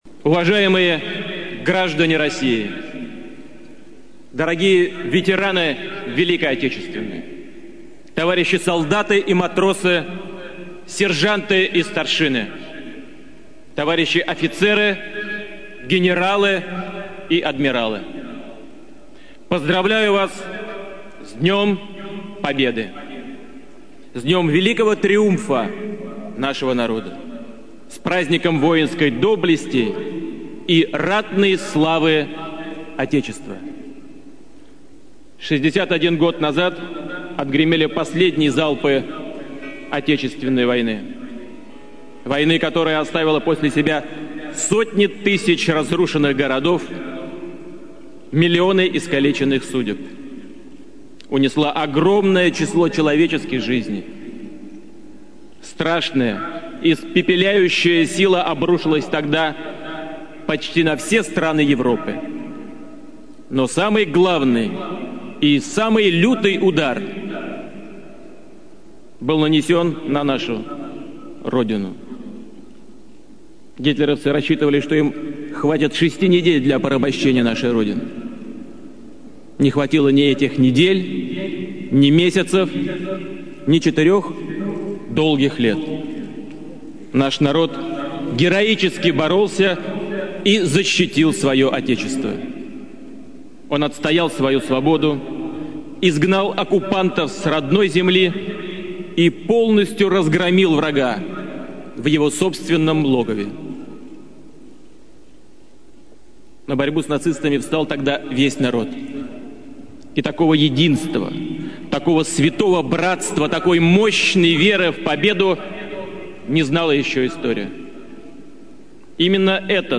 DarikNews audio: Реч на президента Путин по повод 61-ата годишнина от Деня на победата